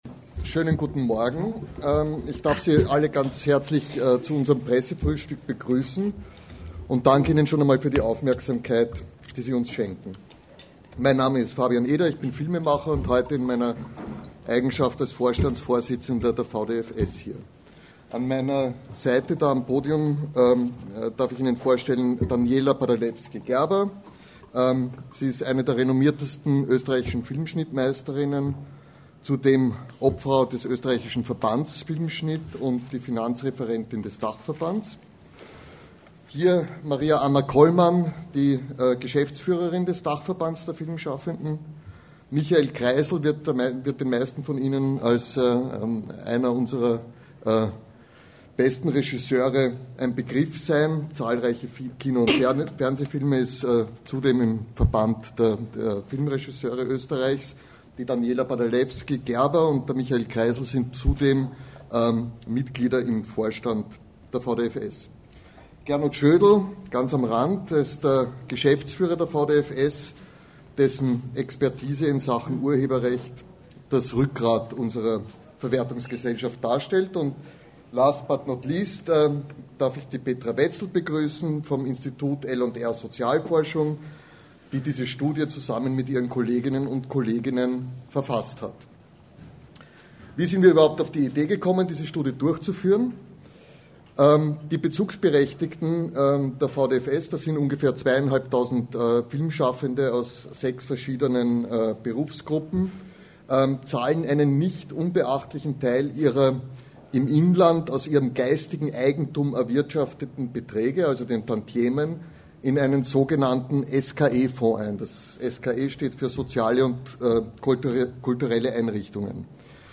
Im Rahmen eines Pressegesprächs wurden am 16. März 2016 die Ergebnisse der von der VdFS initiierten Studie zur sozialen Lage österreichischer Filmschaffender präsentiert.
Die - in ihrer Drastik teils überraschenden - Ergebnisse, die bei dem Pressegespräch gemeinsam mit den StudienautorInnen vorgestellt wurden, zeigen, dass es unumgänglich ist, rasch auf die veränderte Lebensrealität mit Reformen zu reagieren.